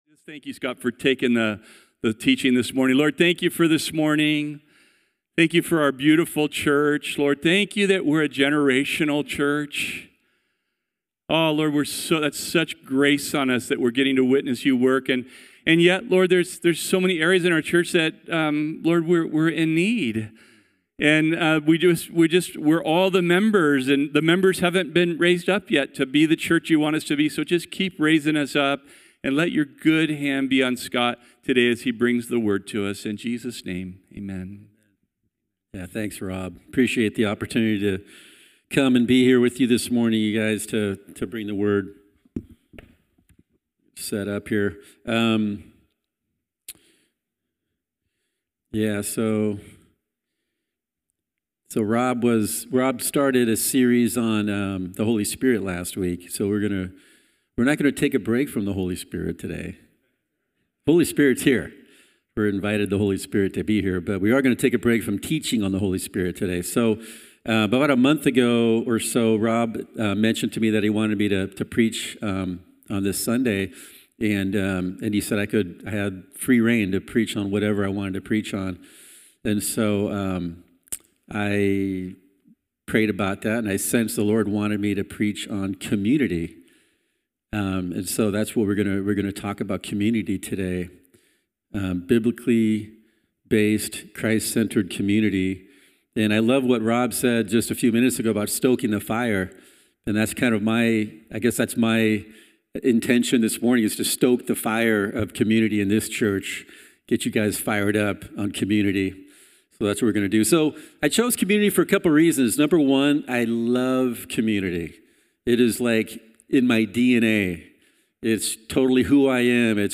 Bible studies given at Calvary Corvallis (Oregon).